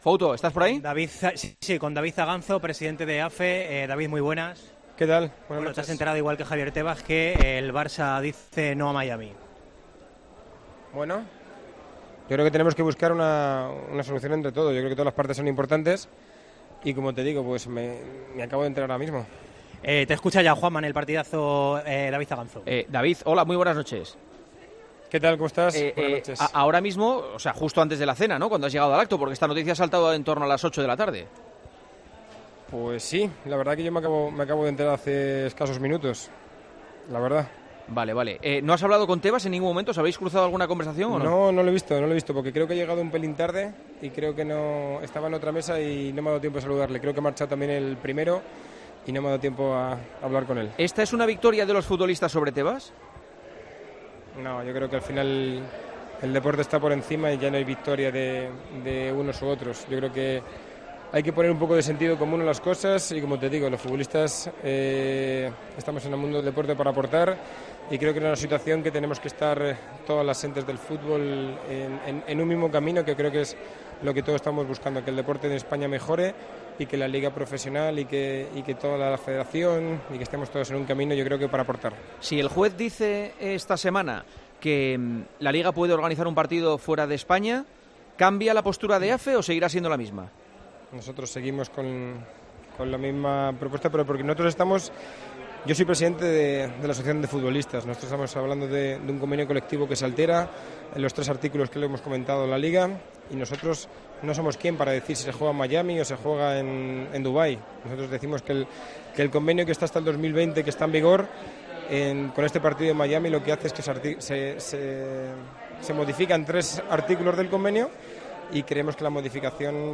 Aganzo, que se enteró durante de la entrevista de la decisión del Barcelona de dejar sin efecto su disposición de jugar en Miami ante el Girona, destacó que como sindicato "tenemos que buscar una solución entre las partes.